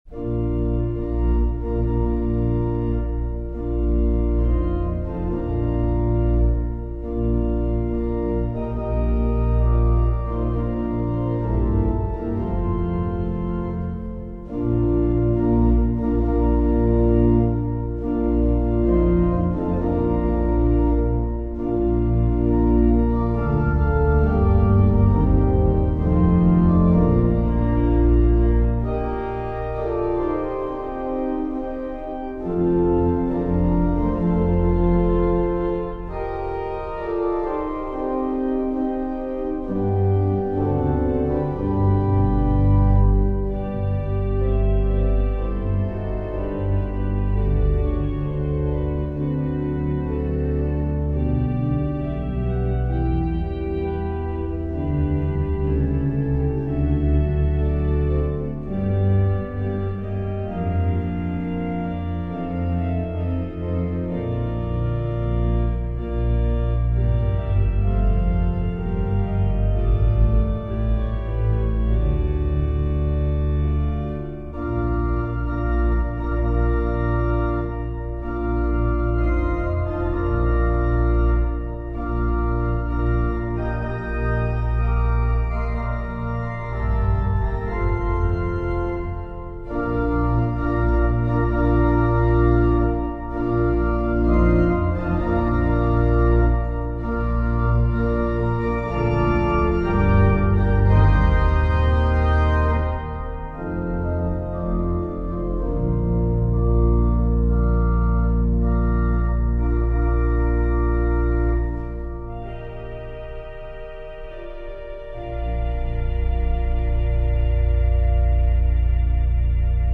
Richard-Wagner-Lohengrin-Hochzeitsmarsch.mp3